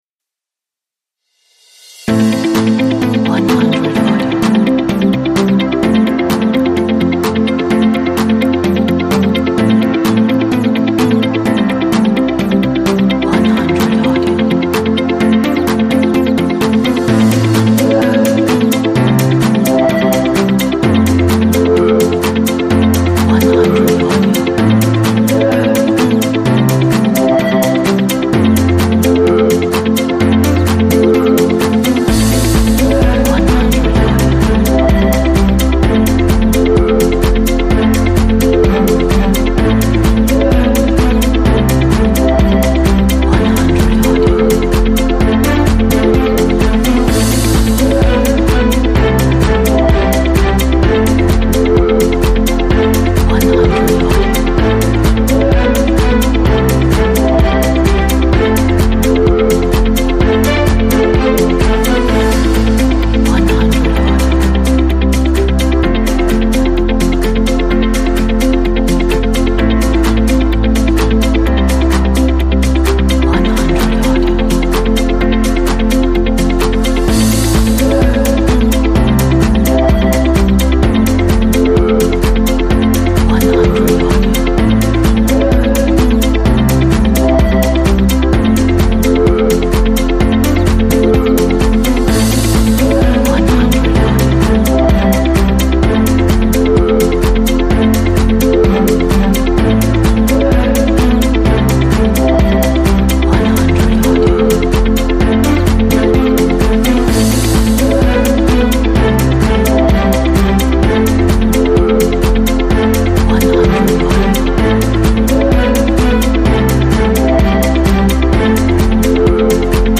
Action Progressive Dance Music - Very inspiring!
Motivated motivational upbeat power energy.